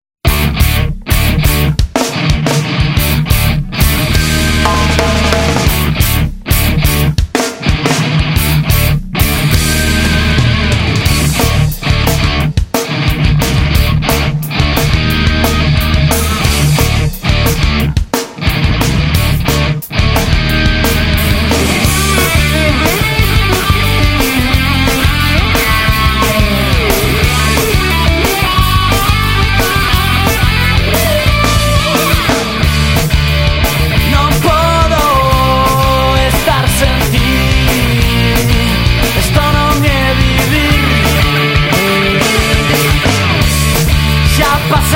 Pop / Rock